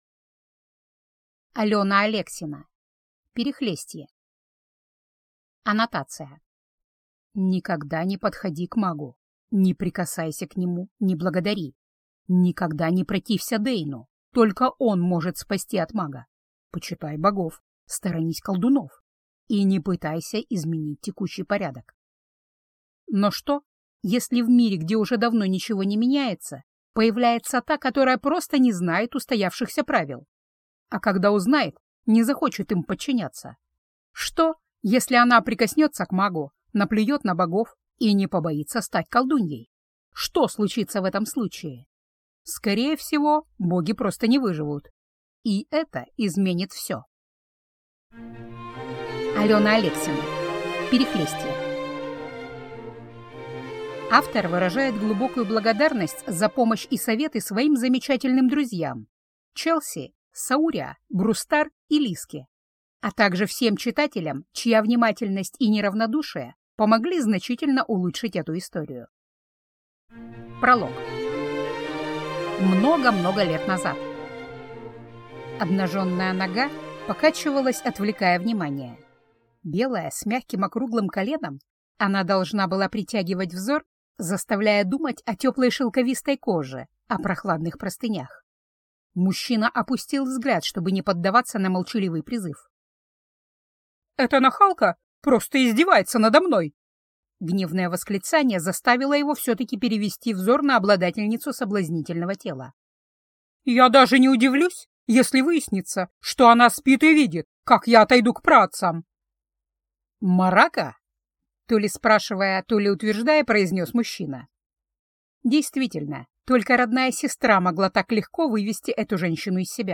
Аудиокнига Перехлестье | Библиотека аудиокниг
Прослушать и бесплатно скачать фрагмент аудиокниги